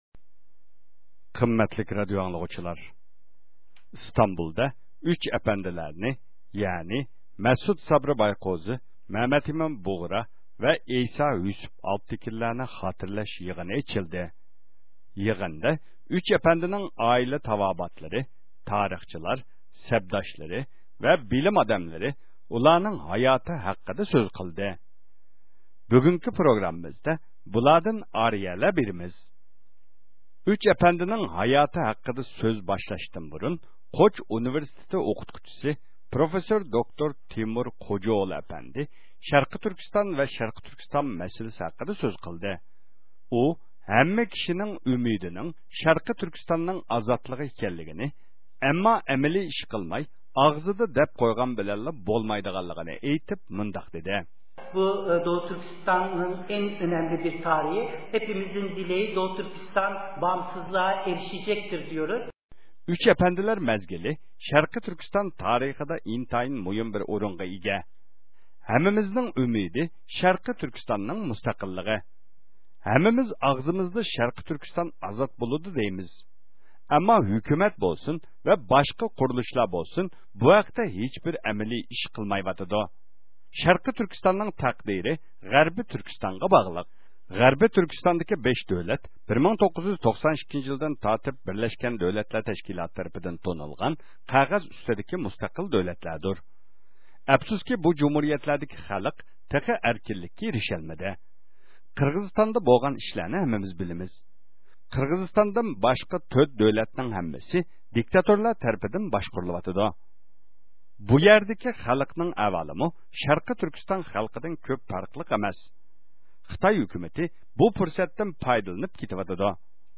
ئۈچ ئەپەندىنى خاتىرىلەش يىغىنىدا قىلىنغان نۇتۇقلاردىن ئارىيىلەر – ئۇيغۇر مىللى ھەركىتى
12-ئىيۇن كۈنى دۇنيا ئۇيغۇر قۇرۇلتىيى بىلەن شەرقىي تۈركىستان ۋەخپىنىڭ ئورۇنلاشتۇرۇشى بىلەن تۈركىيىنىڭ ئىستانبۇل شەھىرىدىكى سۇلايمانىيە مەدەنىيەت مەركىزىدە ئۈچ ئەپەندى – مەسۇت سابىر بايكۆزى، مەھمەت ئەمىن بۇغرا ۋە ئەيسا يۈسۈف ئالپتەكىننى خاتىرىلەش پائالىيىتى بولۇپ ئۆتتى.